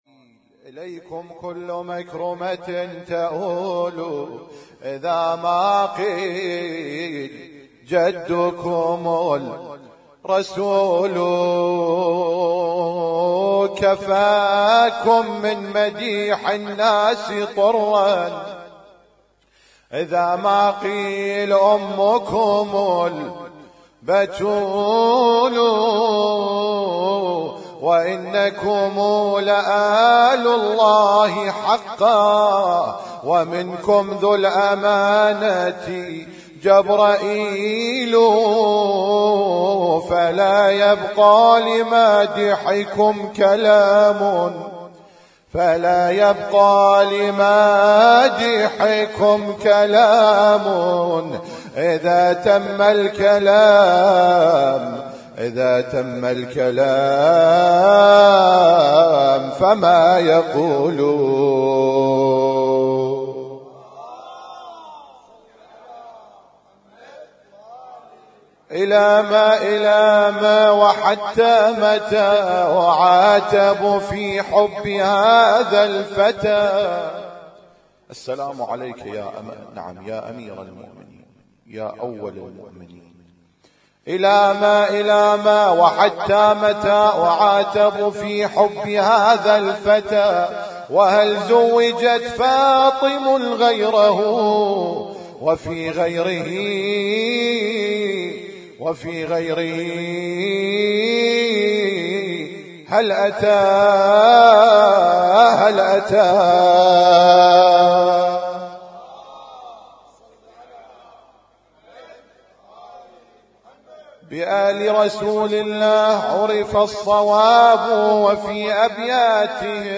قصائد مدح أهل البيت عليهم السلام 1438
اسم التصنيف: المـكتبة الصــوتيه >> المواليد >> المواليد 1438